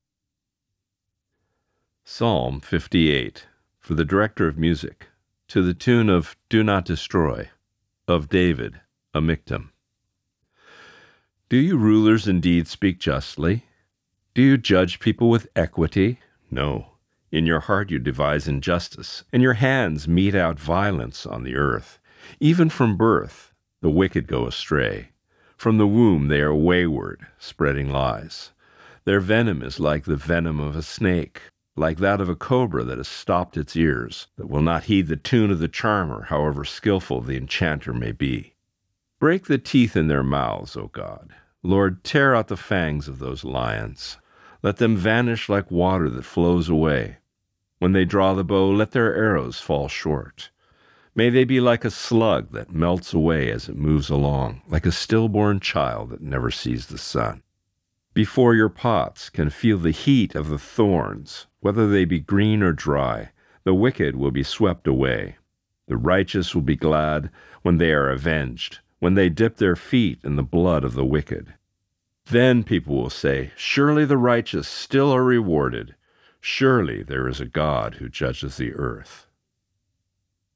Reading:  Psalm 58 (NIV)*